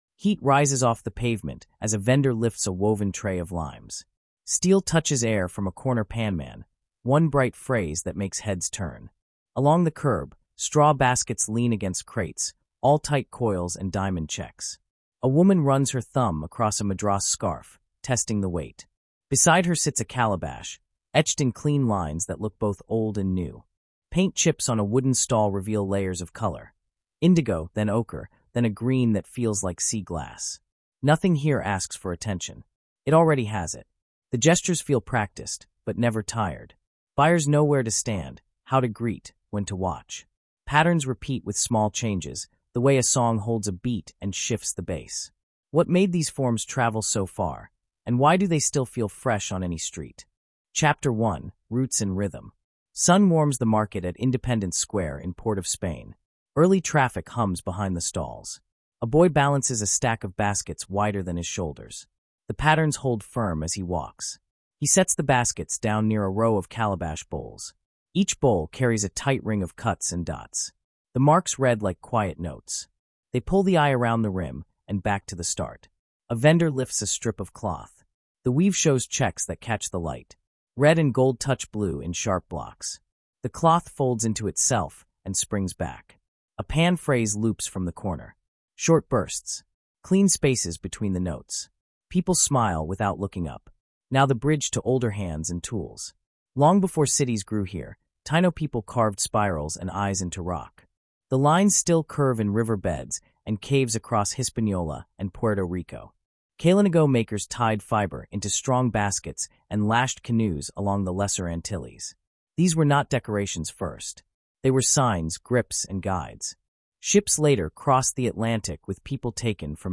Explore how Caribbean history and island culture shape global art and fashion in this compelling episode. Discover the stories behind madras patterns, headwraps, and Carnival frames, alongside the vibrant rhythms of reggae, dancehall, and soca. This documentary narrative highlights the journeys of people and places that have propelled local craftsmanship into a worldwide phenomenon.